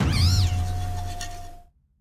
Cri de Fongus-Furie dans Pokémon Écarlate et Violet.